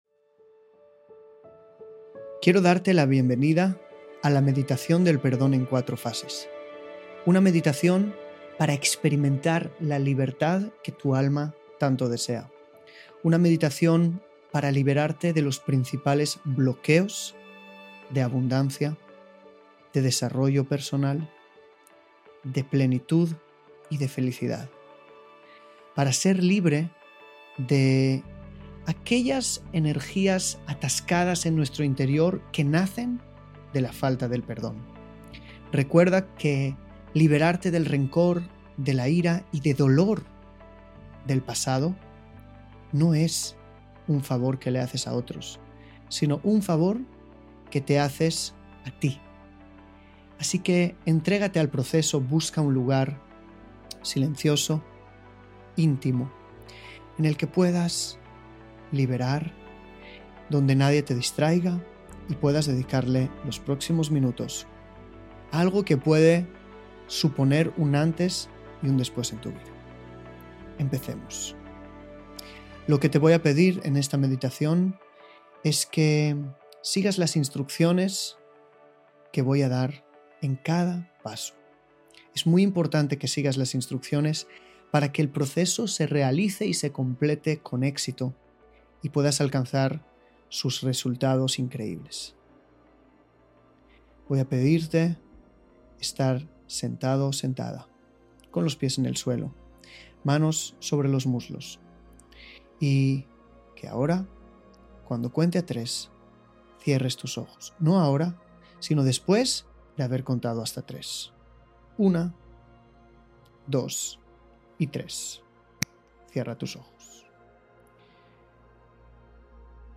meditacion_perdon.mp3